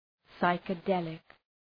Προφορά
{,saıkı’delık}